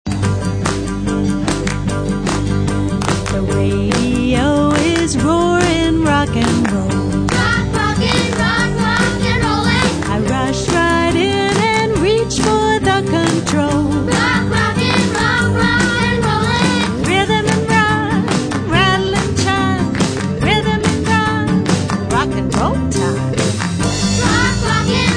Phonics Song Lyrics